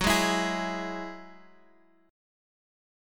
F#7sus2 chord